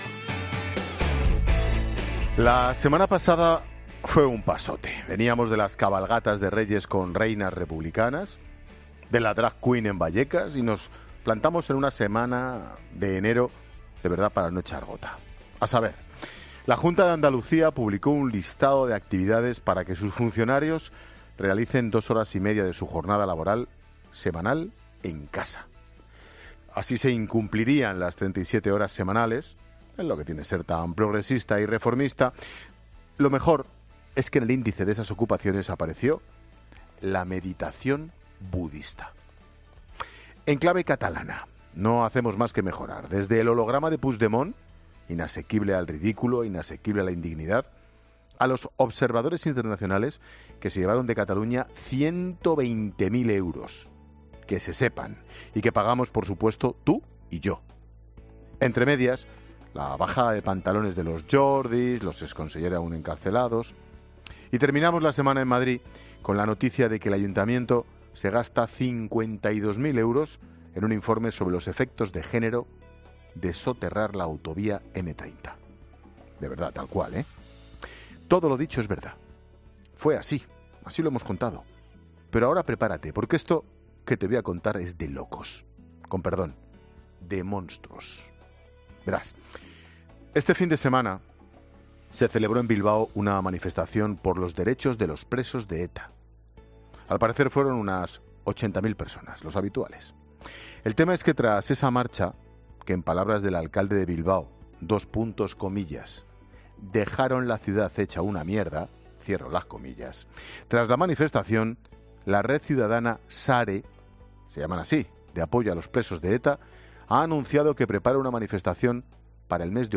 Monólogo de Expósito
El comentario de Ángel Expósito sobre la manifestación en Bilbao a favor de los presos de ETA y la que se prepara en Madrid.